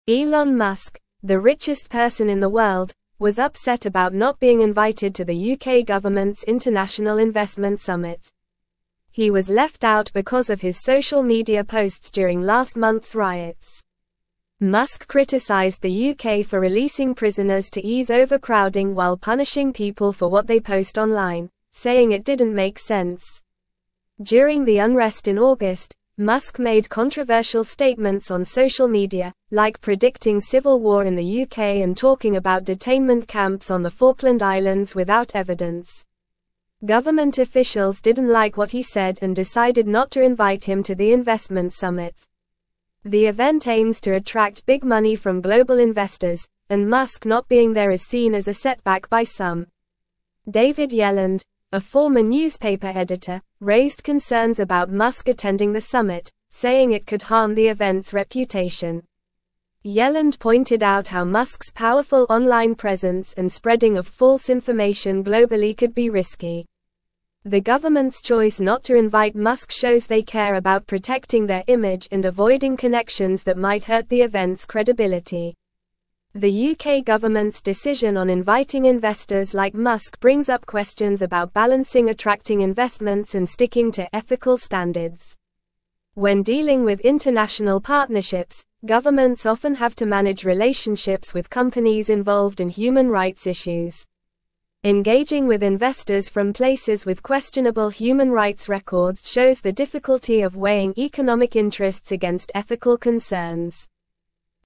Normal
English-Newsroom-Upper-Intermediate-NORMAL-Reading-Elon-Musk-Excluded-from-UK-Investment-Summit-Over-Posts.mp3